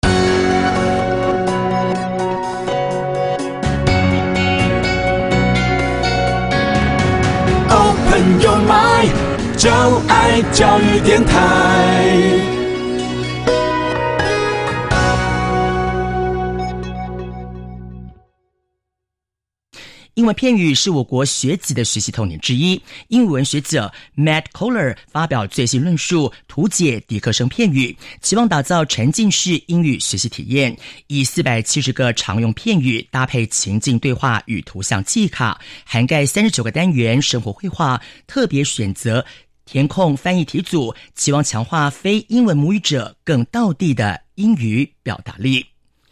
本書對話、片語及例句皆由專業外師錄製朗誦，只要掃瞄書頁上的QR Code，便能輕鬆聽取。
掃描書封QR Code下載「寂天雲」App，即能下載全書音檔，無論何時何地都能輕鬆聽取專業母語老師的正確道地示範發音，訓練您的聽力。